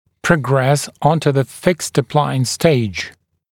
[prə’gres ‘ɔntə ðə fɪkst ə’plaɪəns steɪʤ][прэ’грэс ‘онтэ зэ фикст э’плайэнс стэйдж]перейти к стадии лечения несъемными аппаратами